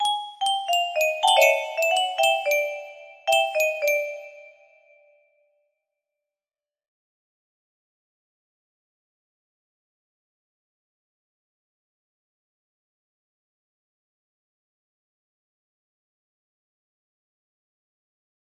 aaaaa music box melody